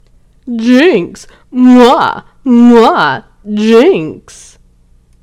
infinitefusion-e18/Audio/SE/Cries/JYNX.mp3 at releases-April